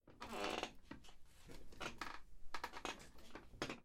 Foley practice " 27 Silla chirreando
描述：Foley练习椅
Tag: 实践 椅子 弗利